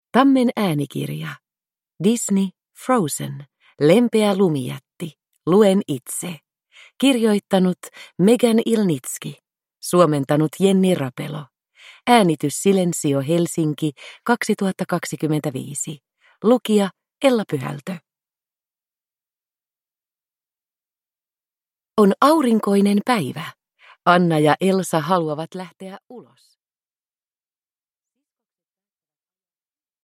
Disney. Frozen. Lempeä lumijätti. Luen itse – Ljudbok